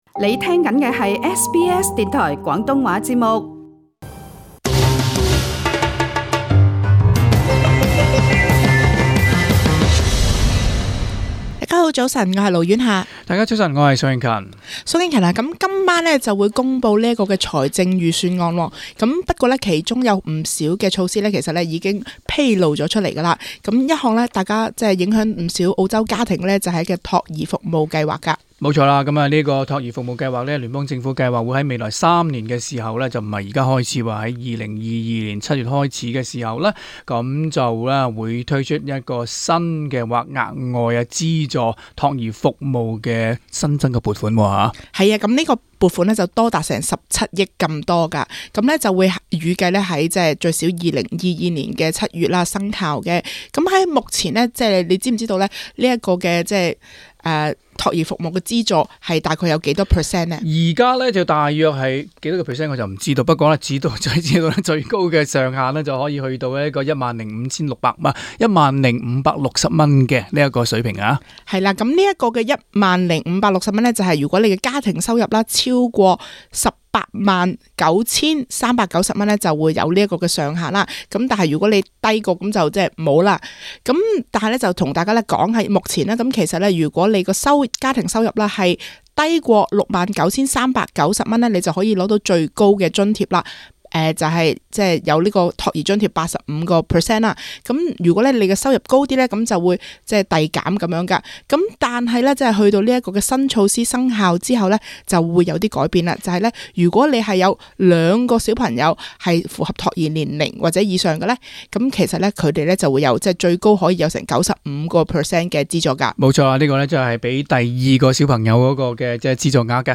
詳情請收聽本節時事報道。